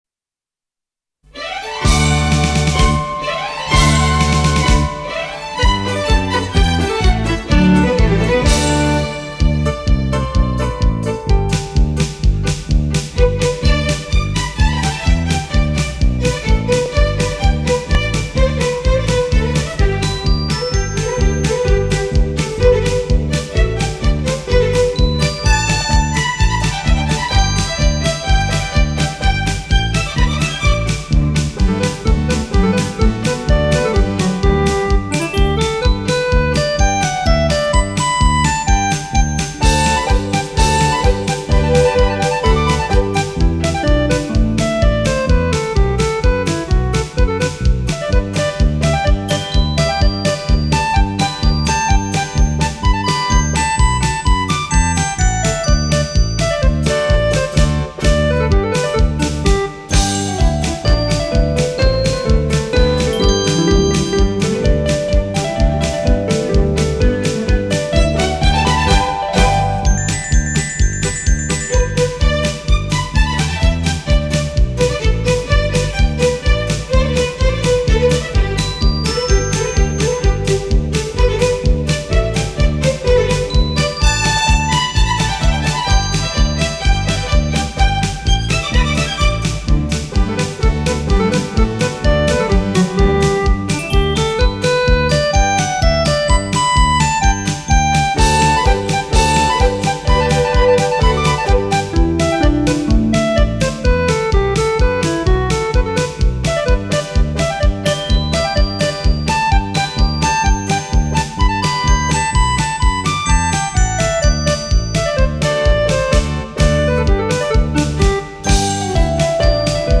音乐类型：民乐
常规器乐有粤胡、秦琴、琵琶、扬琴、洞箫、喉管、笙及木鱼、铃。
旋律轻快激昂，层层递增，节奏明快，音浪叠起叠落，一张一弛，音乐富有动力，给人以奋发上进的积极意义。